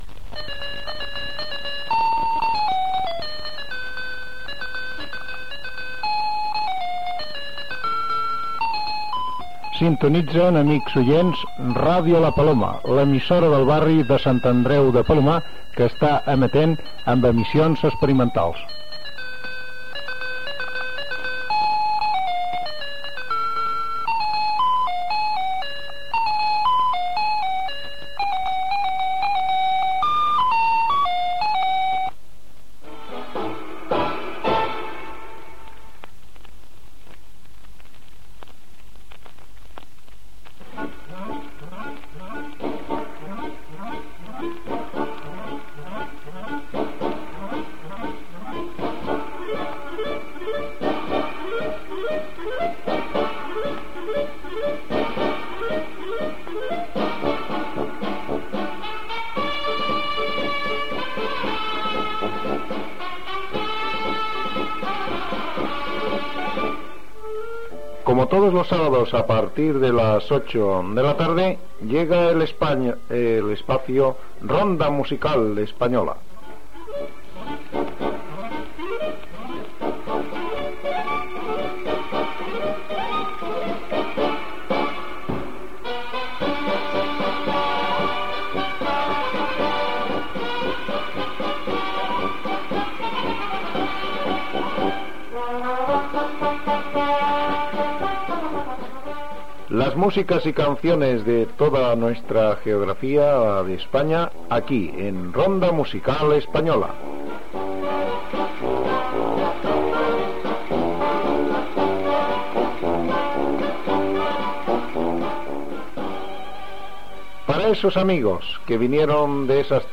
Indicatiu i inici del programa
Musical
FM